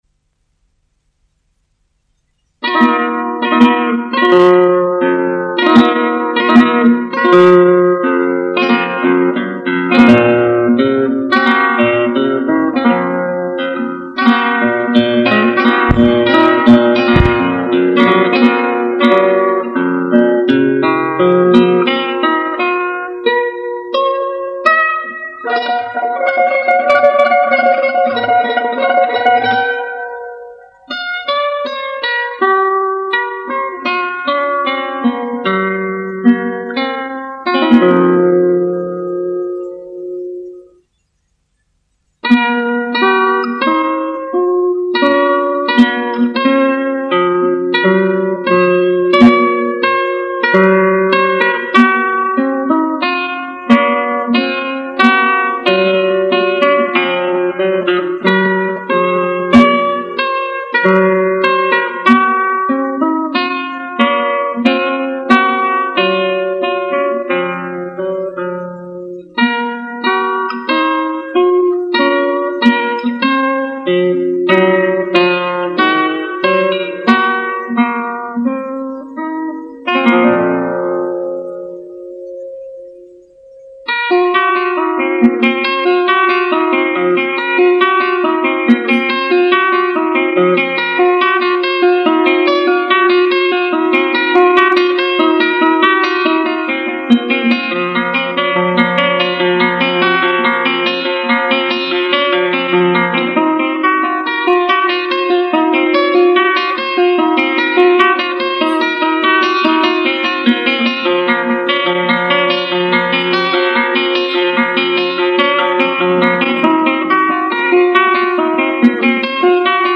[6/10/2011]西班牙古典吉他独奏《樱花变奏曲》MP3